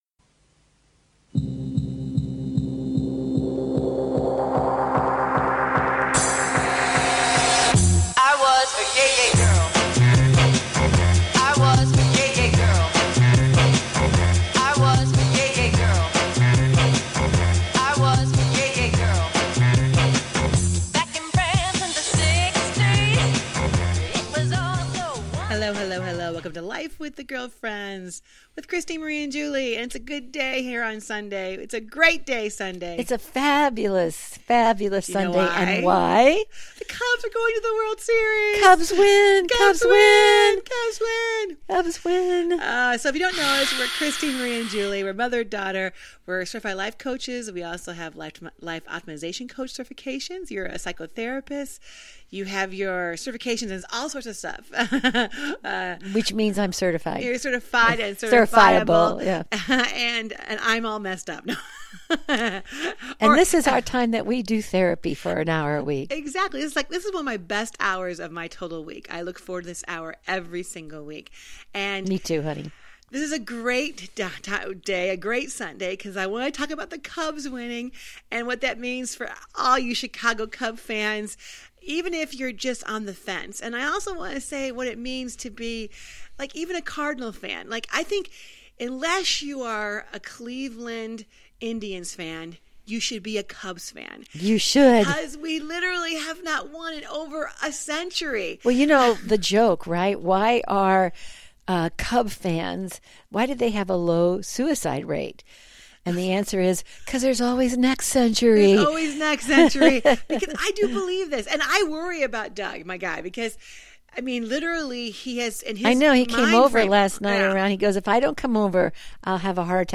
Talk Show Episode
And join the girlfriends up close and personal for some daily chat that’s humorous, wholesome, and heartfelt.